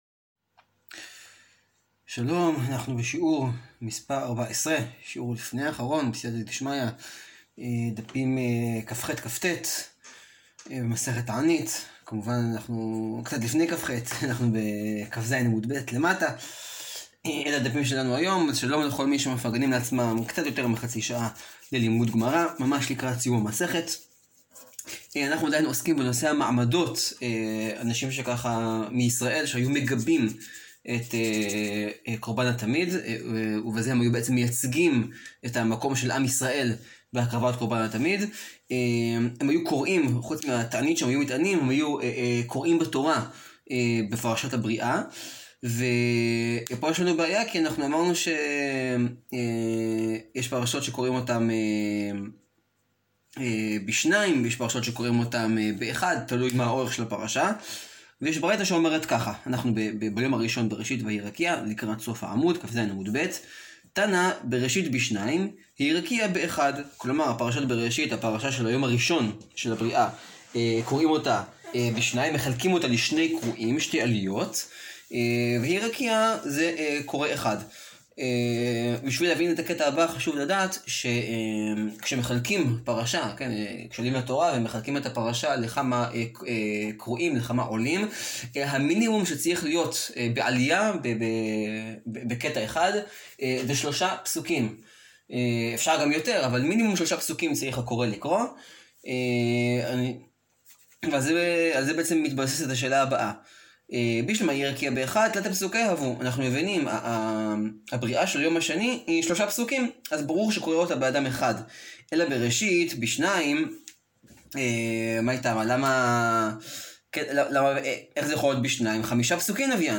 שיעור 13 להאזנה: מסכת תענית, דפים כח-כט.